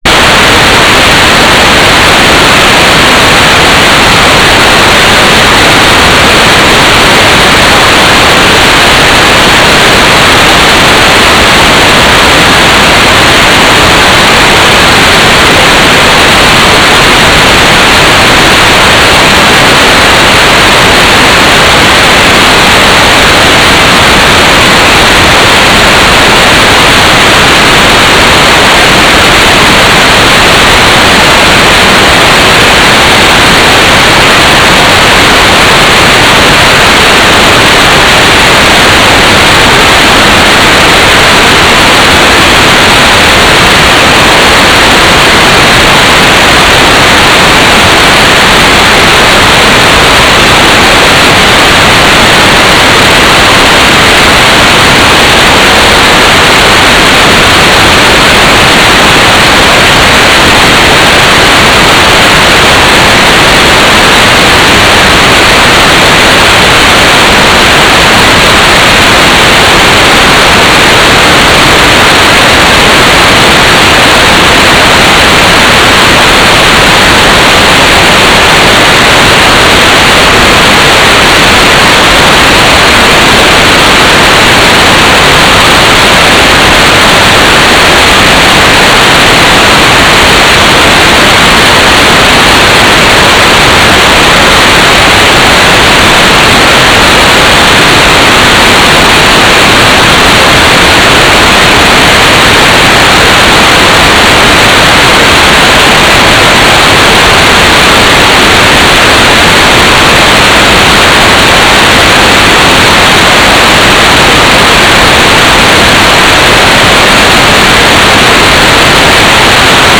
"transmitter_description": "GFSK Telemetry",